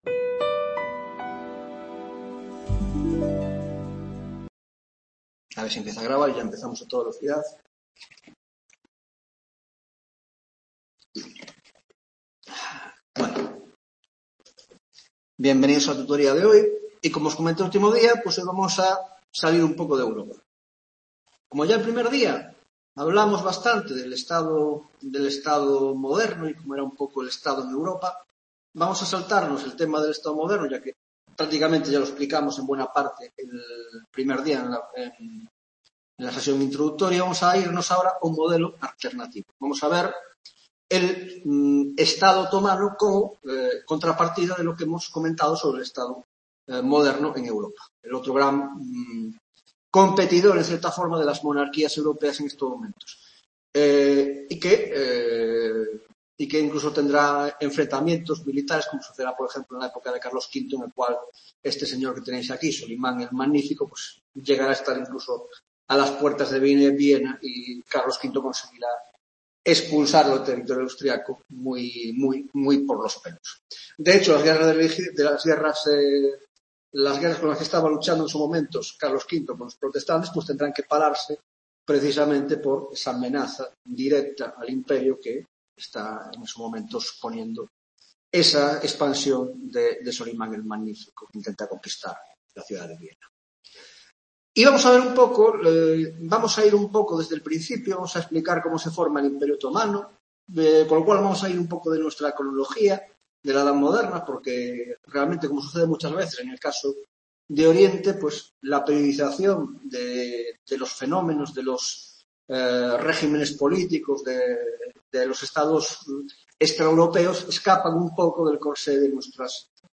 10ª Tutoría Historia Moderna (Grado de Antropología Social y Cultural): 1) El Imperio Otomano (1ª parte): 1.1) Introducción, orígenes y expansión del Imperio Otomano, 1.2) Administración Territorial, 1.3) la Organización Económica (Propiedad y Fiscalidad)